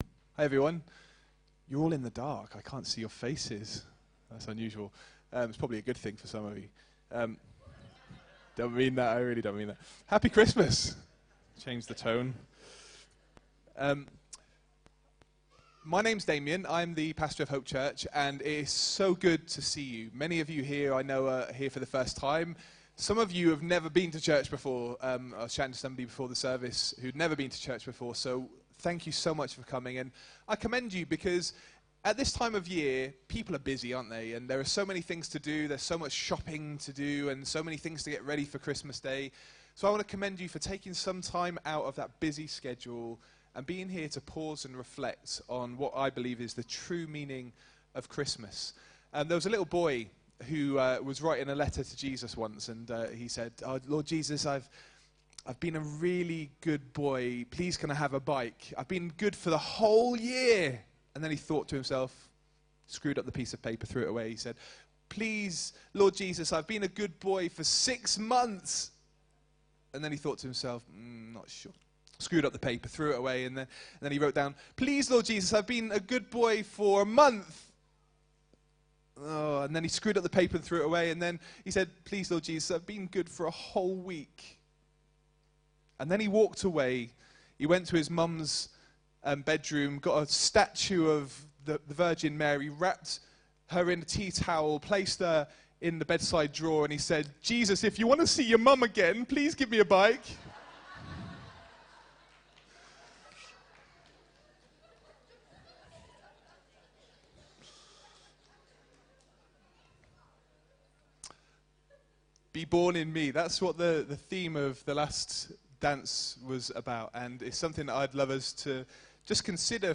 be-born-in-us-christmas-message-2019.mp3